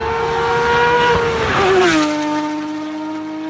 911 GT3 Exhaust Ringtone
Category car-engine-sounds